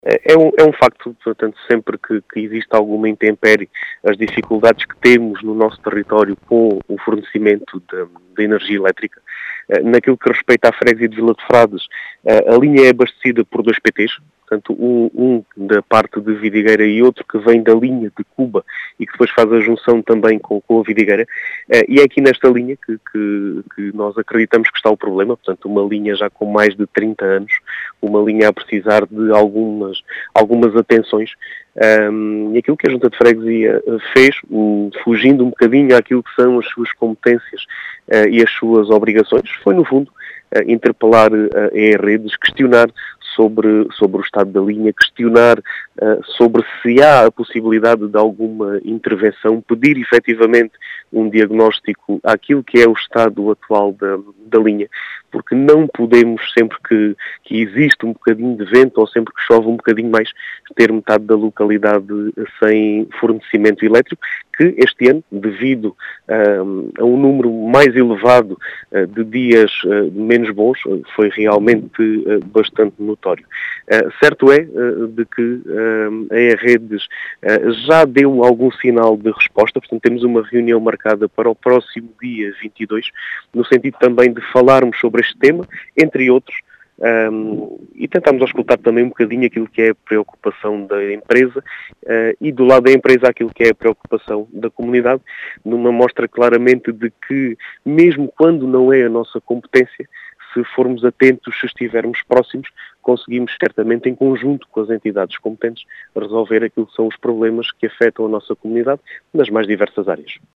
As explicações são de Diogo Conqueiro, presidente da junta de freguesia de Vila de Frades, que diz já ter obtido resposta da E-Redes, com o agendamento de uma reunião.